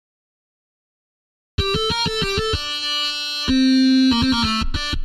描述：电吉他，电子管放大
Tag: 95 bpm Hip Hop Loops Guitar Electric Loops 870.97 KB wav Key : G